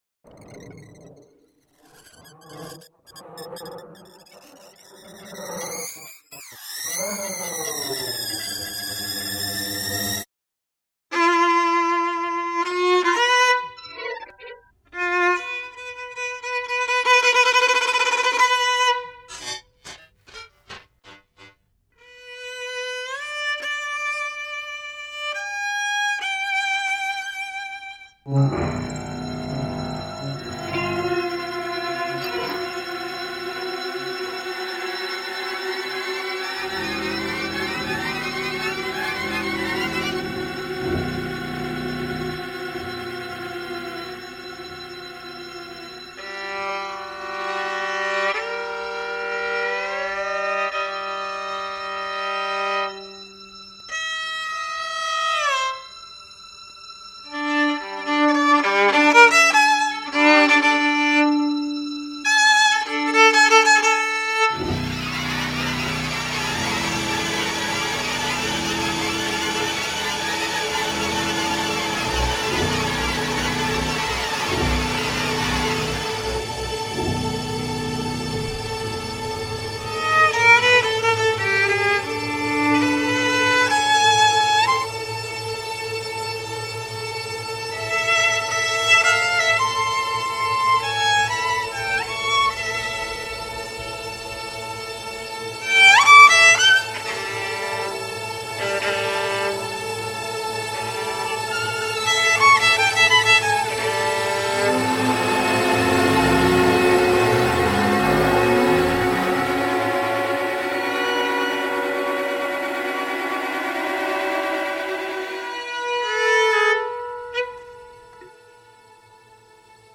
for violin and electronics